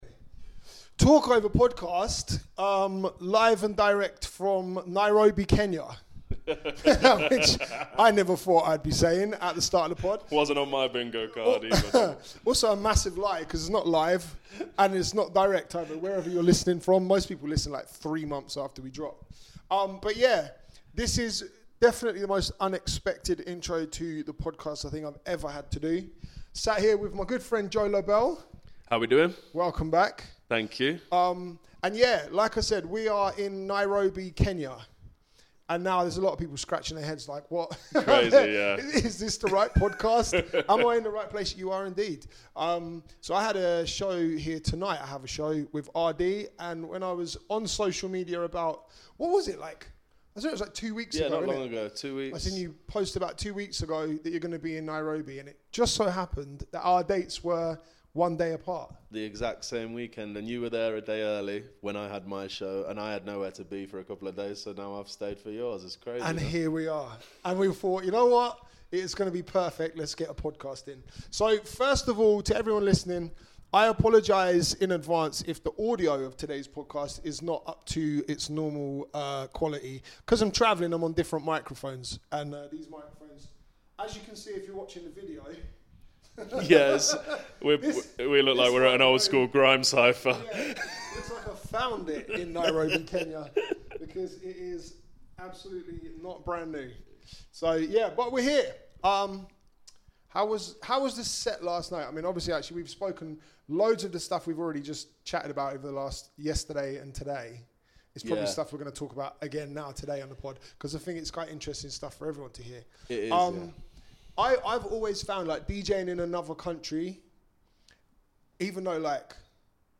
This week we're recording in Nairobi, Kenya!